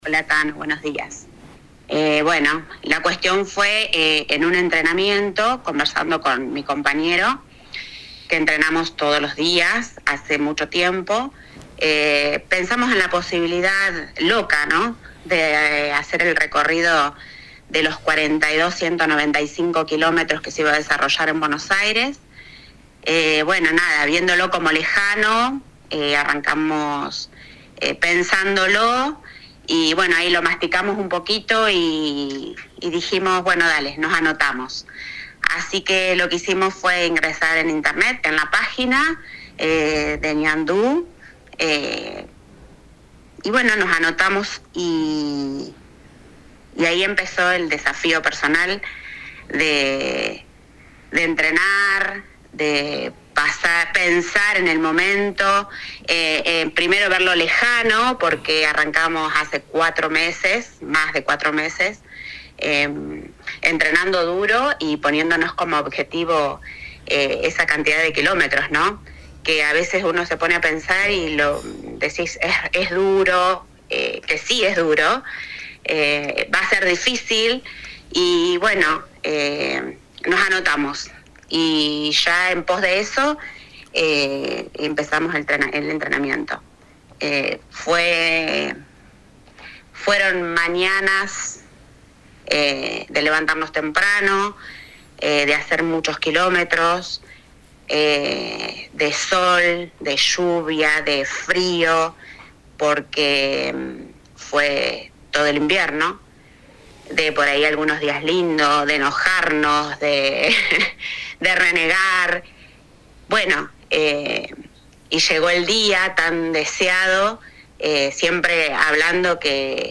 AUDIO DE LA ENTREVISTA ( en dos bloques )